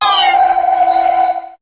Cri de Cresselia dans Pokémon HOME.